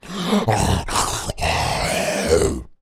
zombie_eating_1.wav